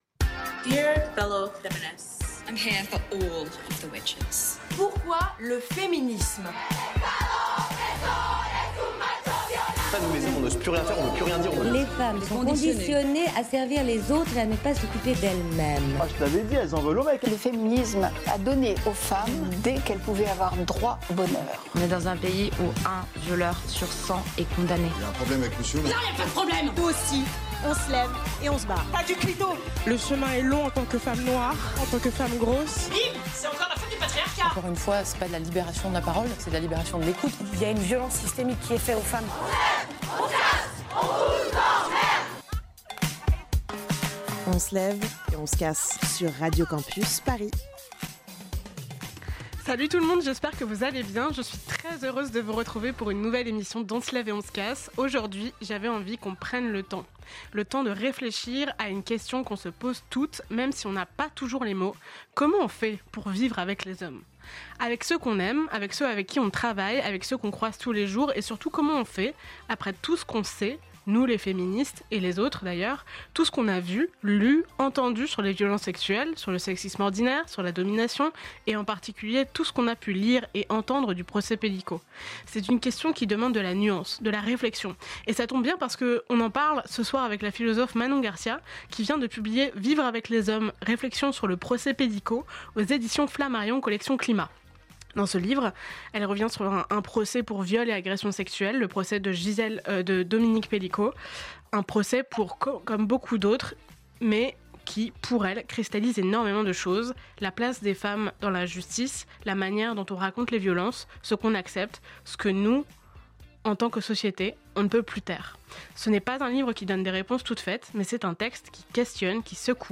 🎙 Avec la philosophe Manon Garcia
Dans cet épisode, nous recevons la philosophe féministe Manon Garcia, autrice de Vivre avec les hommes – Réflexions sur le procès Pélicot (éditions Flammarion, Climats). Ensemble, nous analysons ce procès hors norme, aussi appelé procès de Mazan, au prisme de la justice, du patriarcat, de l'hétérosexualité comme système politique et des responsabilités masculines.